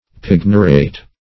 Search Result for " pignerate" : The Collaborative International Dictionary of English v.0.48: Pignerate \Pig"ner*ate\, v. t. [L. pigneratus, p. p. of pignerate to pledge.] 1.